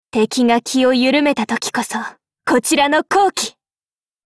贡献 ） 协议：Copyright，人物： 碧蓝航线:镇海语音 您不可以覆盖此文件。
Cv-50601_battlewarcry.mp3